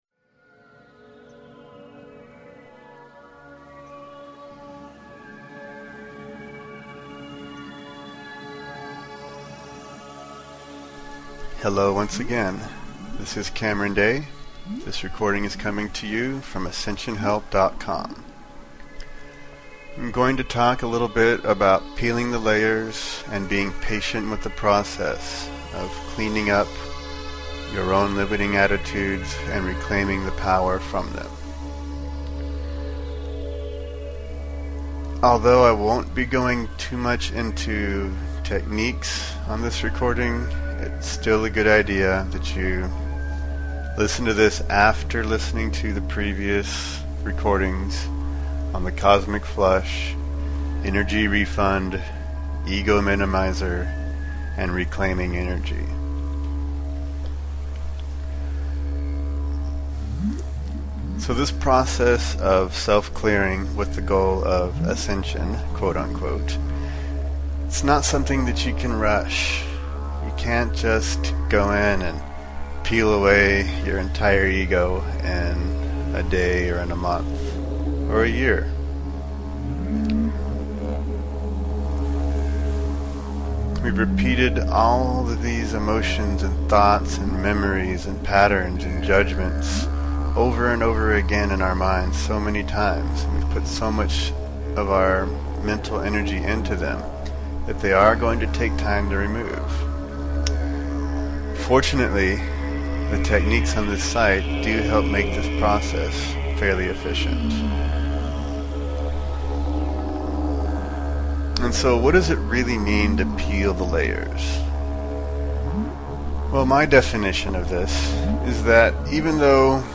peeling-layers-with-didg.mp3